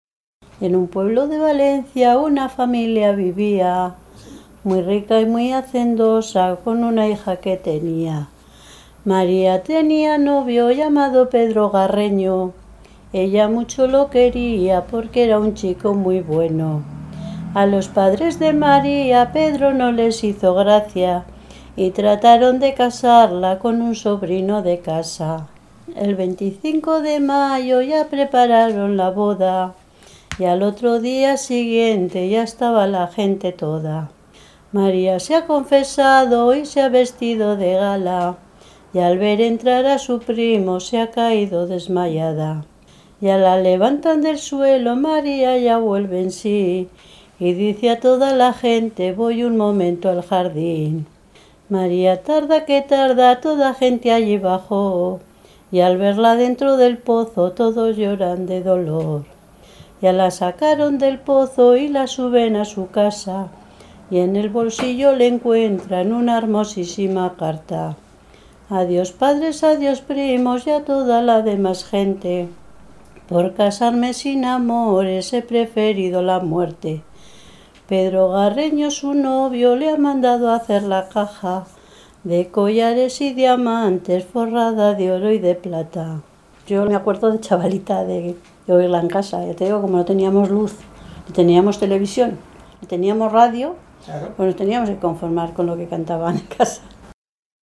Clasificación: Romancero
Localidad: Trevijano de Cameros
Canción narrativa muy del gusto de fines del siglo XIX y comienzos del XX que trataban estos temas de suicidios y muertes por amor.